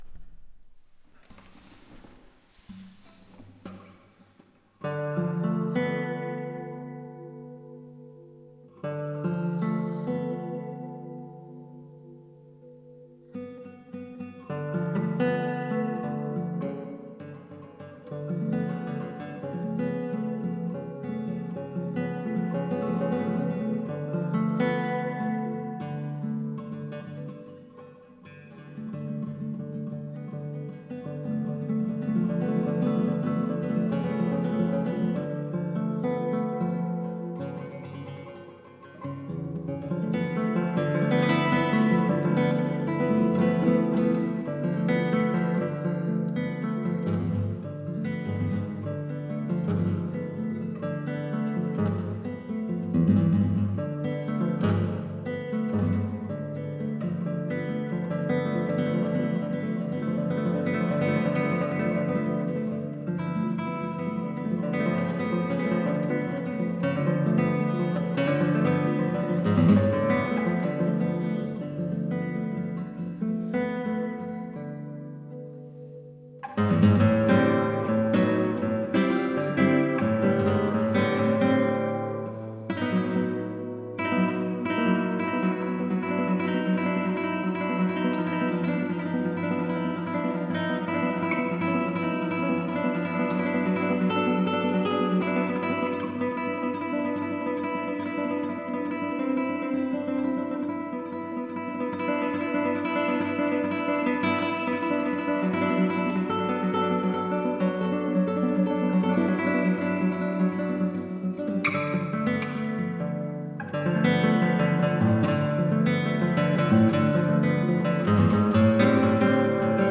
Studio f�r Meistergitarren